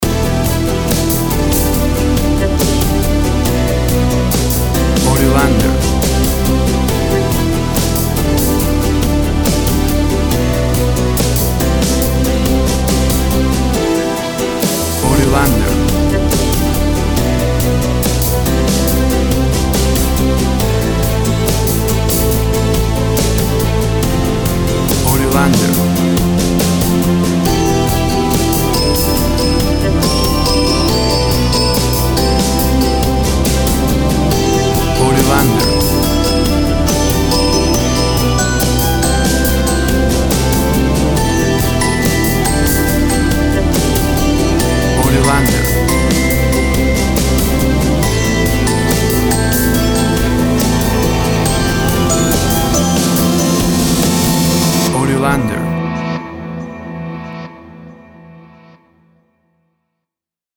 Drama and nostalgic ambient rock sounds.
Tempo (BPM) 70